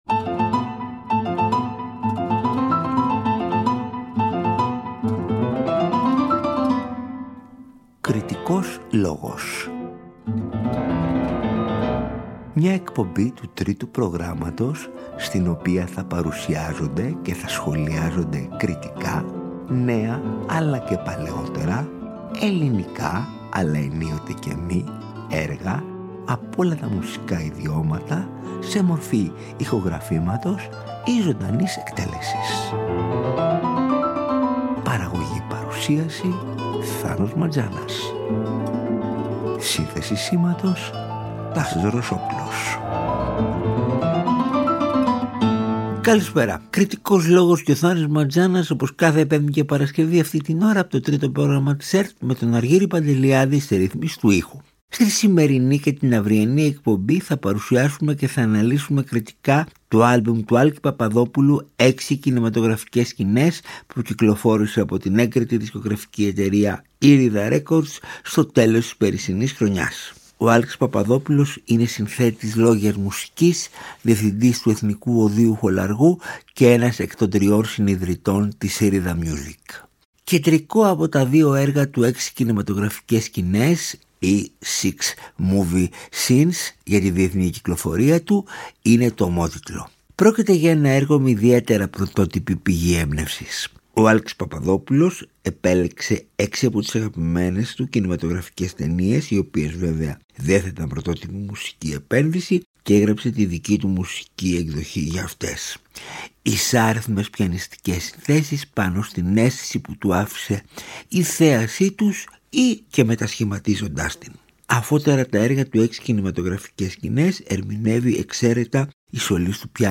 Πρόκειται για ισάριθμες πιανιστικές συνθέσεις πάνω στην αίσθηση που του άφησε η θέαση τους ή και μετασχηματίζοντας την.
Πρόκειται για το έργο «1973» για δύο πιάνα.